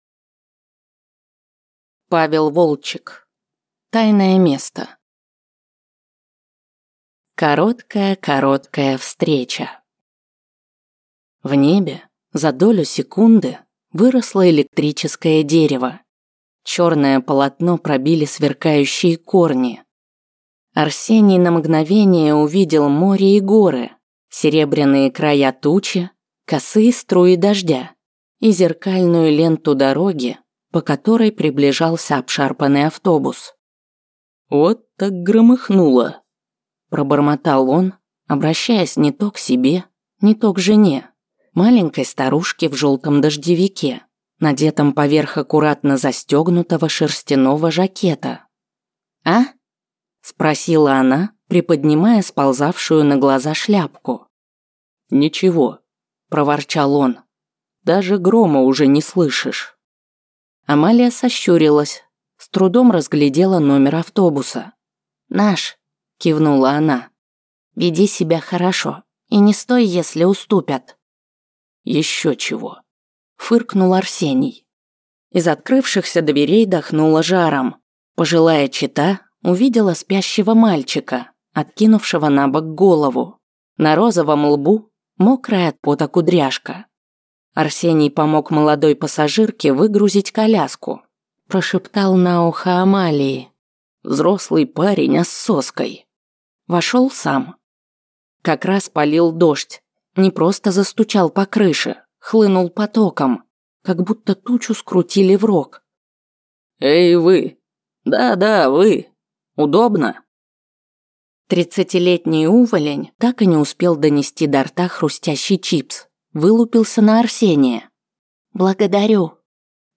Аудиокнига Тайное Место | Библиотека аудиокниг